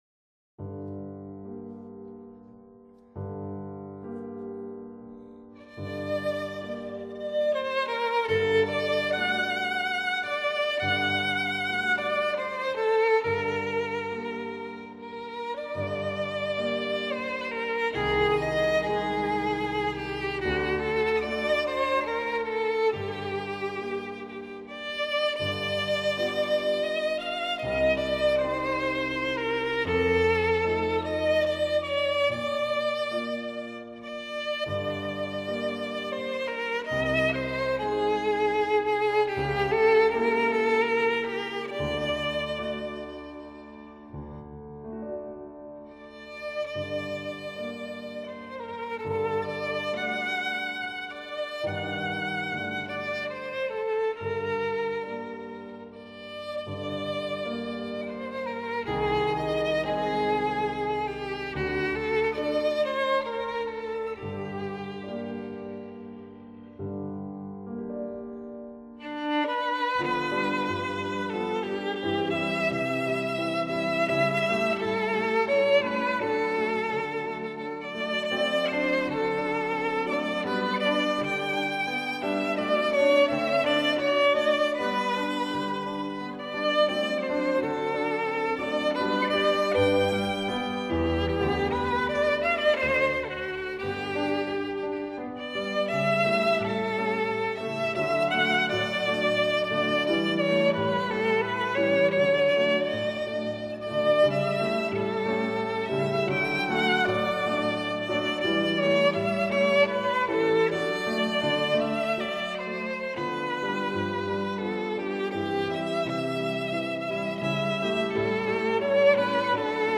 曲调优美，演奏细腻，丝丝入扣
爆发力强，有着惊人的速度和技巧